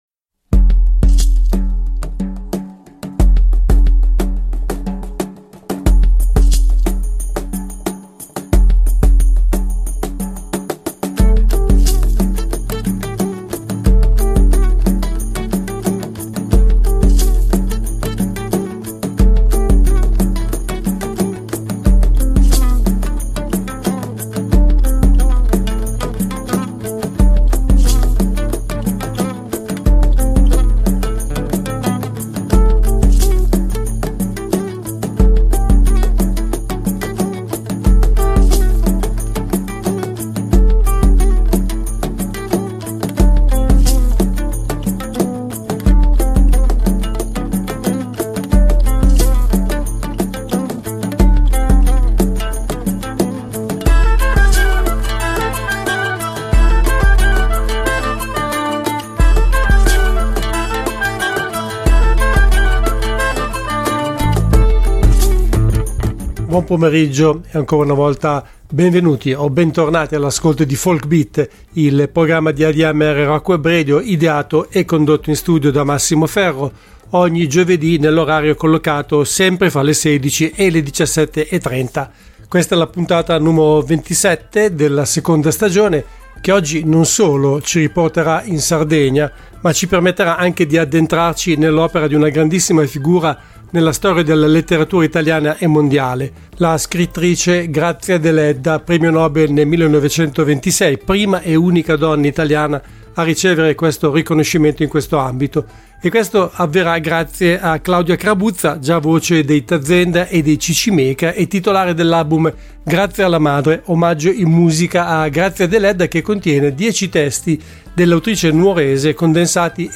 al telefono